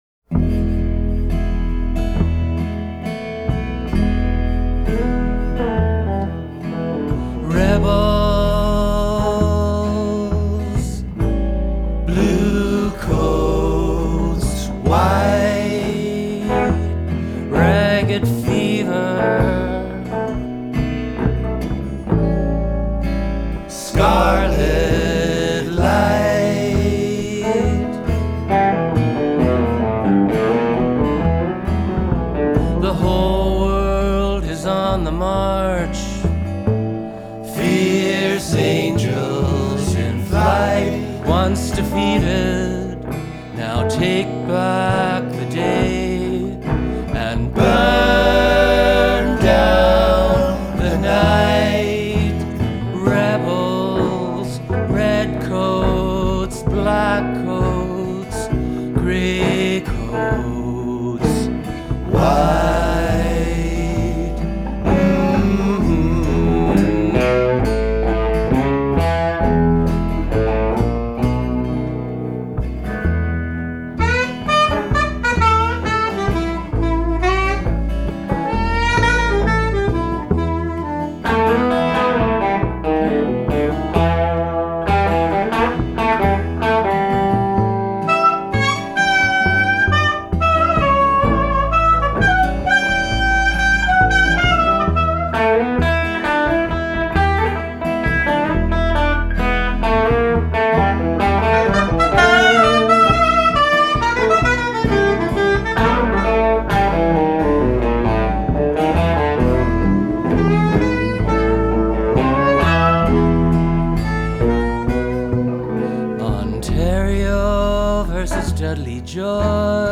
Recorded live at the No Fun Club in Winnipeg
acoustic guitar/vocals
electric guitar/vocals
sax/vocals
bass/vocals